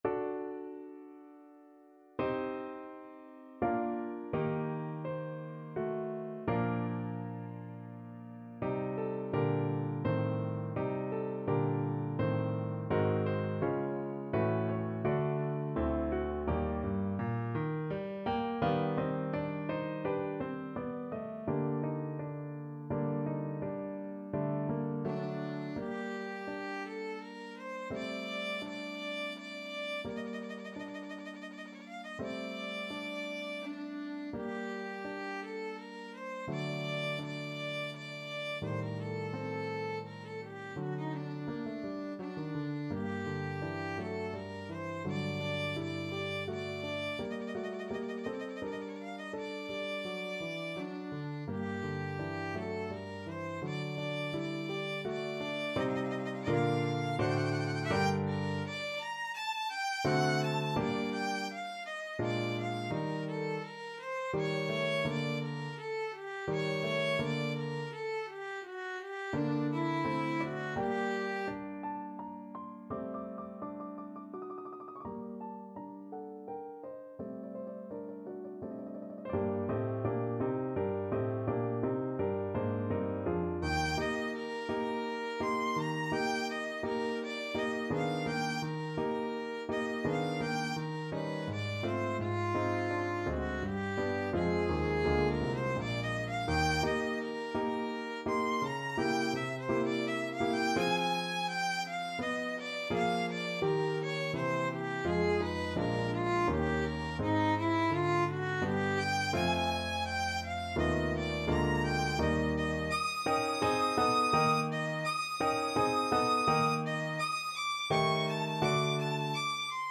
Violin
3/4 (View more 3/4 Music)
G minor (Sounding Pitch) (View more G minor Music for Violin )
Andante =84
Classical (View more Classical Violin Music)